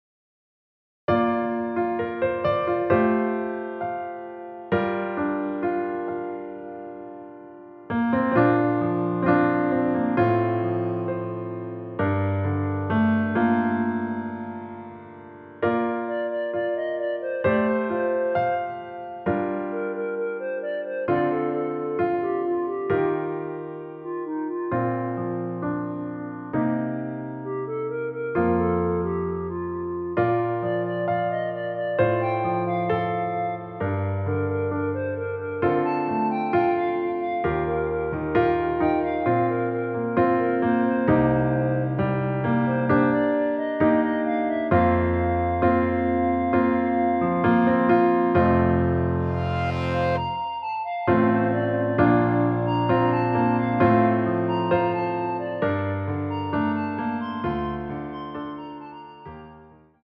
원키에서(-1)내린 멜로디 포함된 MR입니다.
Bb
앞부분30초, 뒷부분30초씩 편집해서 올려 드리고 있습니다.